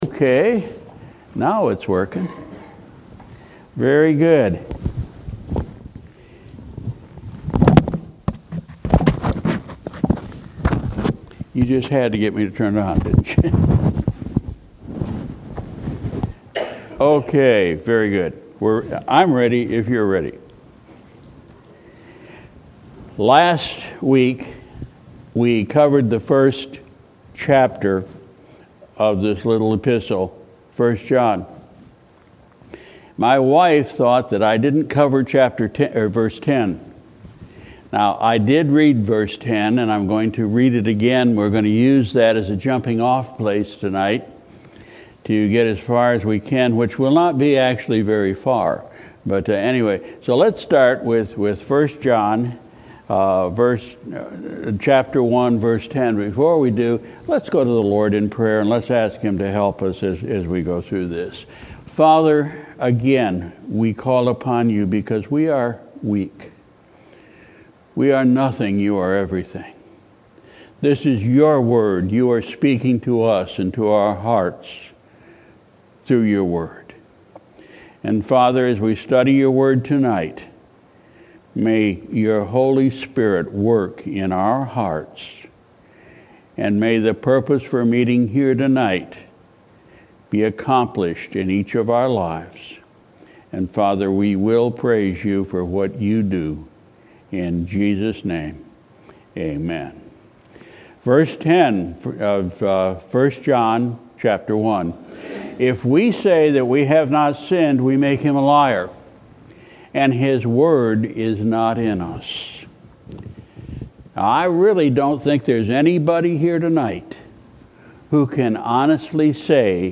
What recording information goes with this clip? Sunday, September 2, 2018 – Evening Service